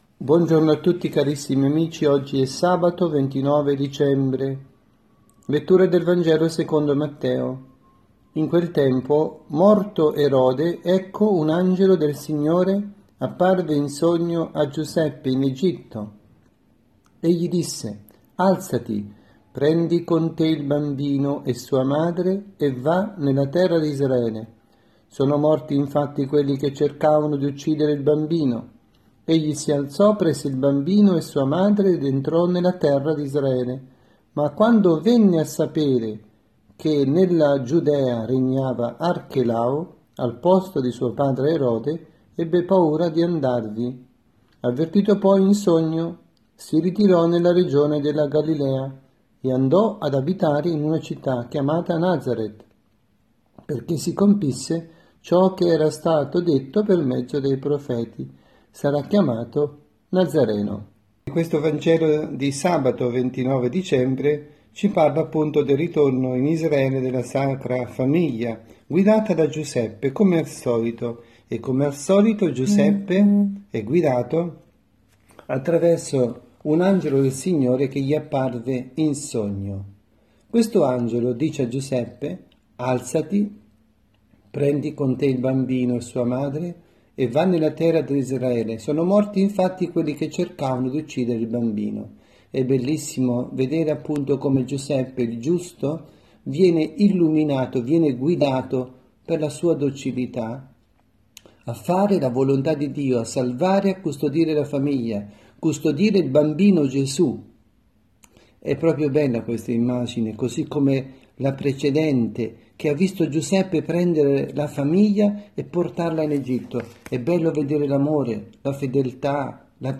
Catechesi
dalla Parrocchia S. Rita – Milano